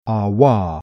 Fricativa labiovelare sorda